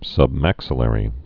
(sŭb-măksə-lĕrē)